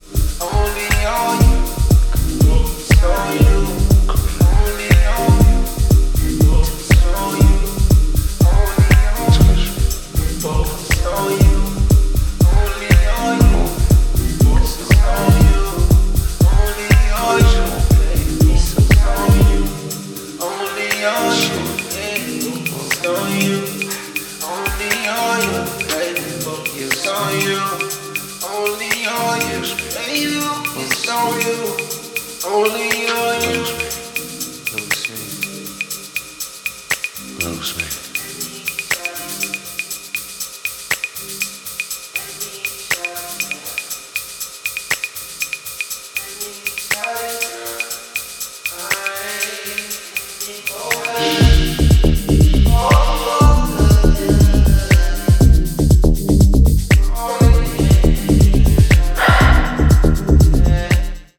deep vocal cut